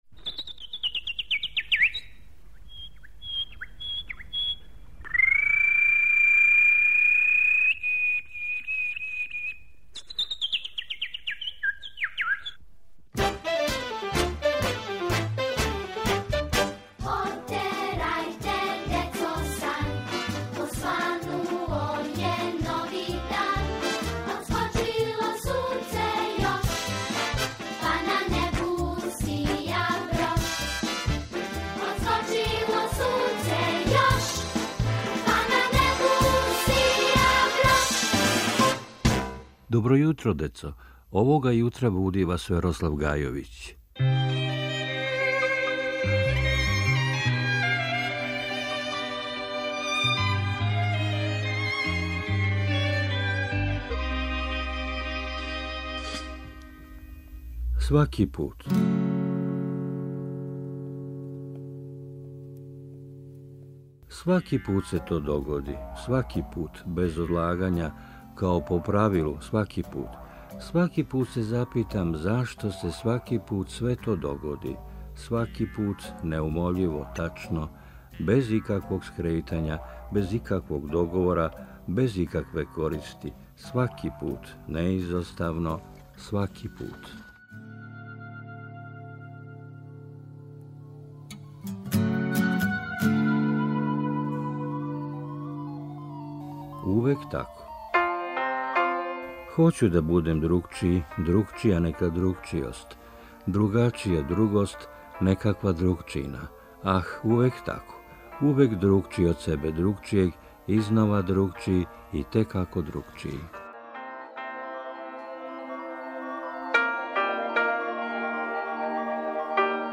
Поезија